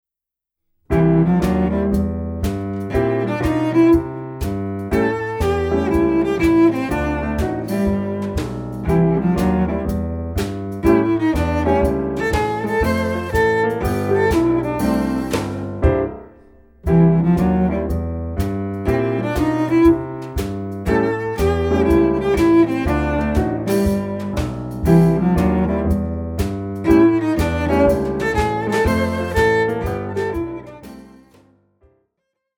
• Besetzung Violoncello und Klavier